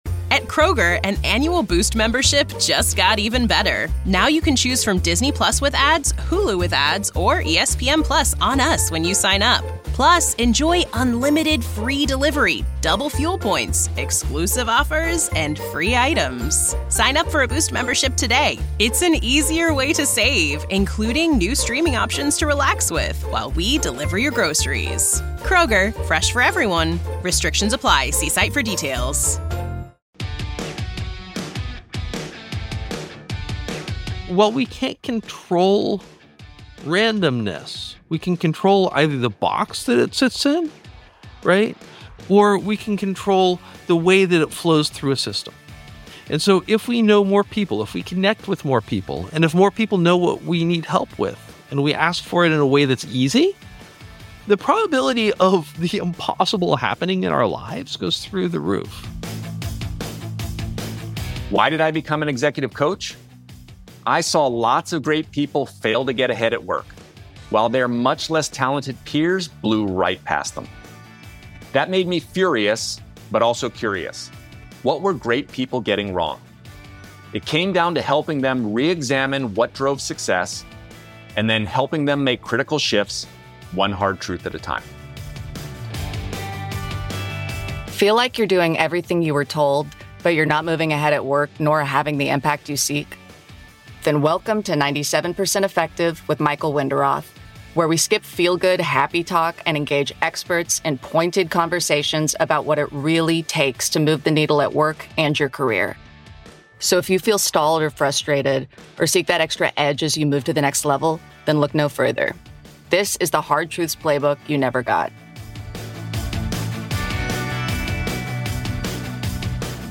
Season #1 focuses on Power and Influence, two widely acknowledged (but poorly understood) forces that can help you rise, lead more effectively and get big things done. Each week we have candid conversations with an academic, coach, or executive, helping you gain new insights to better navigate your work and career.